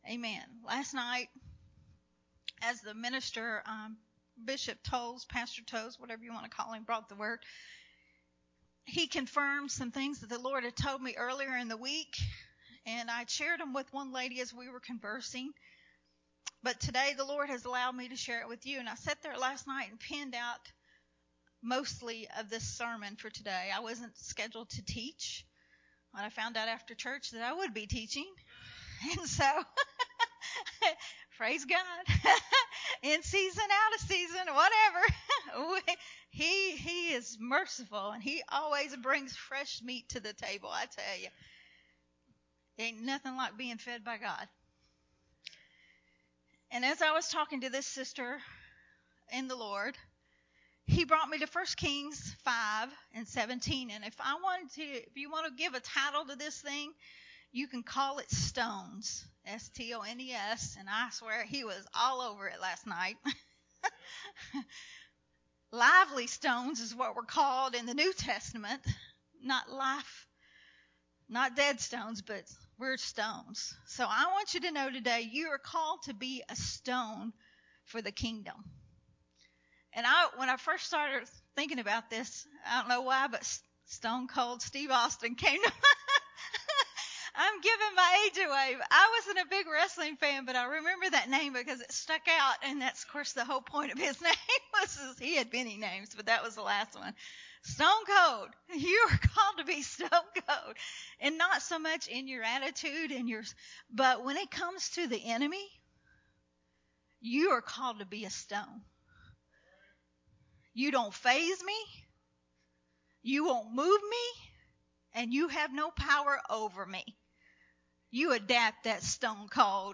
A Sunday Morning Refreshing teaching
recorded at Unity Worship Center on July 17th, 2022.